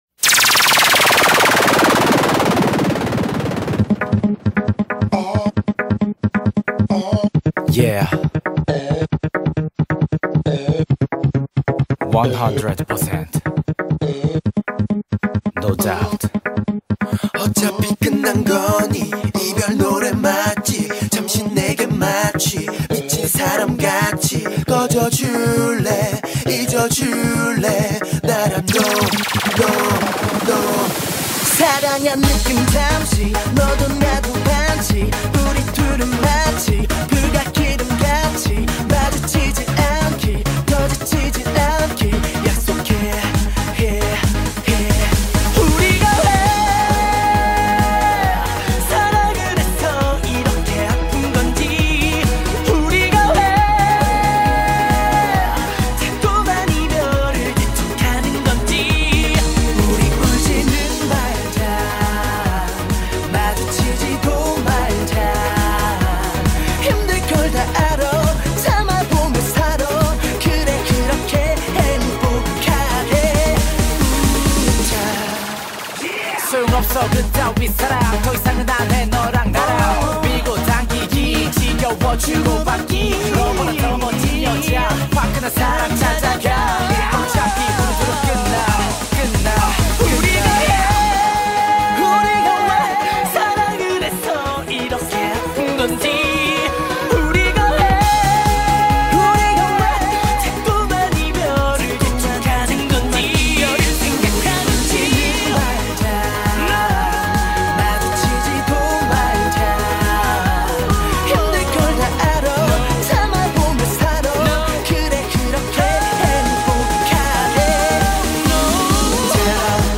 BPM135-270
Audio QualityPerfect (Low Quality)